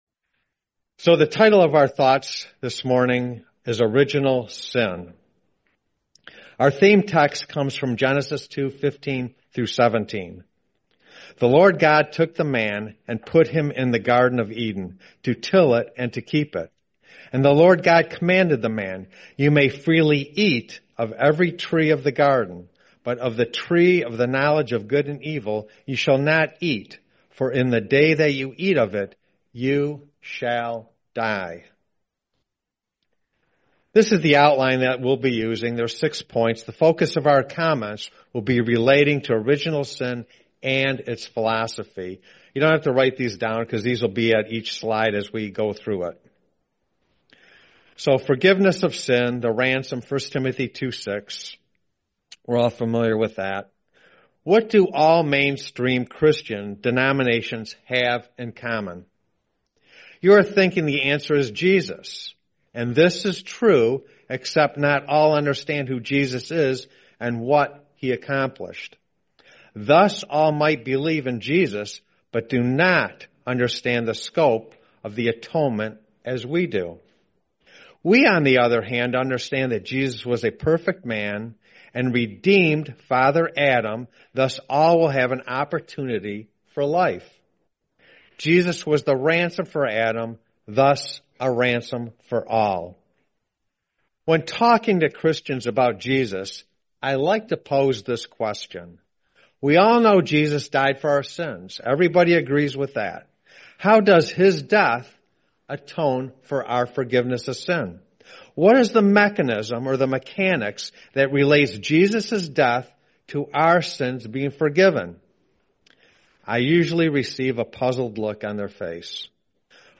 Series: 2025 Sacramento Convention